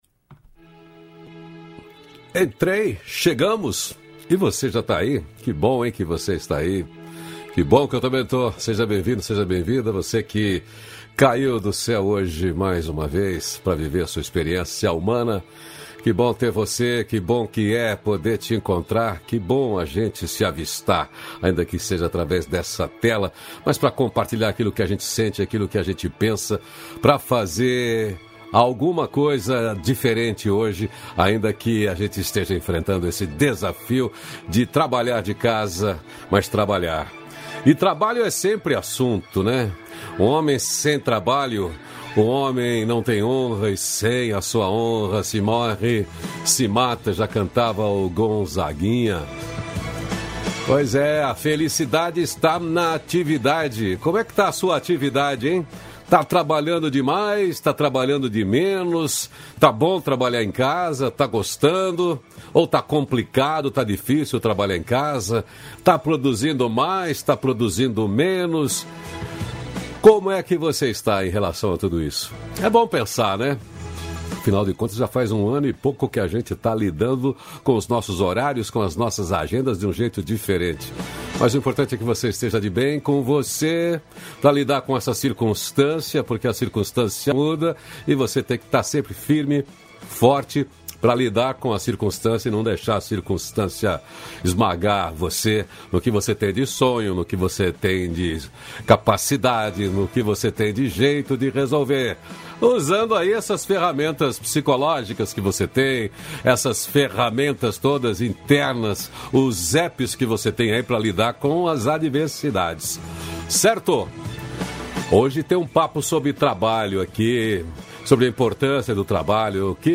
360-feliz-dia-novo-entrevista.mp3